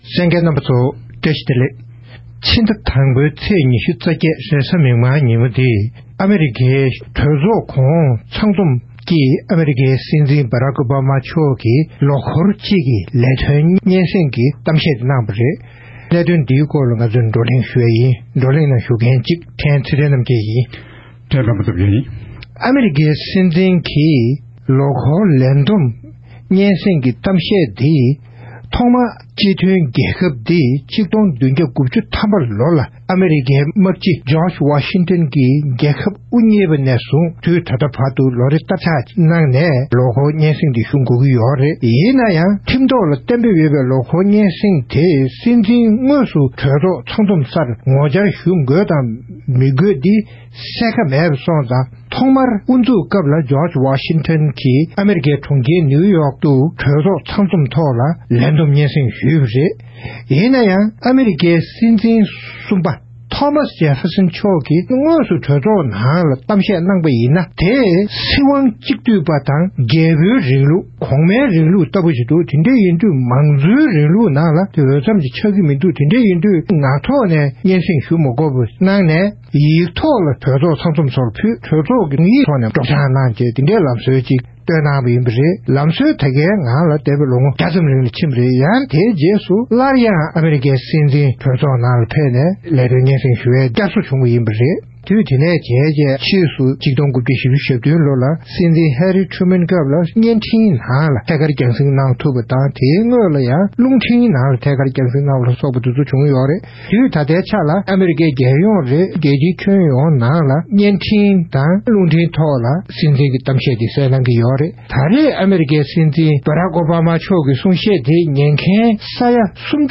གནད་དོན་དེའི་སྐོར་བགྲོ་གླེང་ཞུས་པ་ཞིག་གསན་རོགས་གནང་།